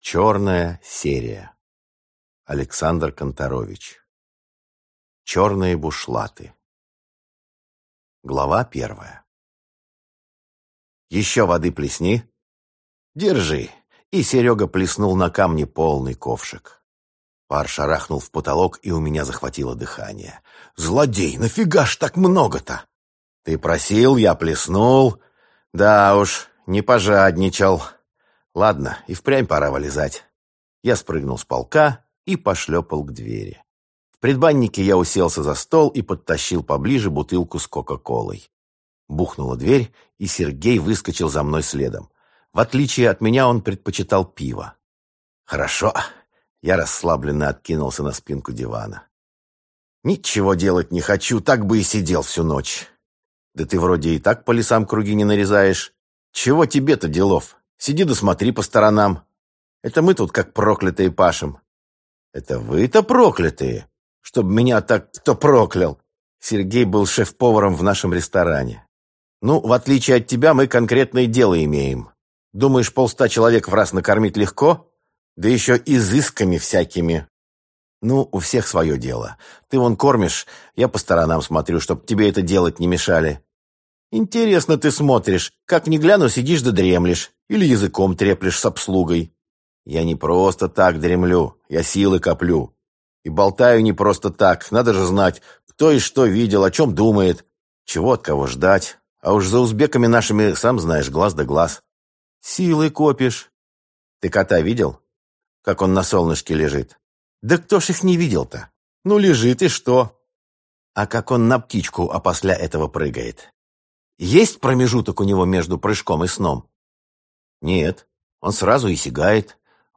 Аудиокнига Черные бушлаты - купить, скачать и слушать онлайн | КнигоПоиск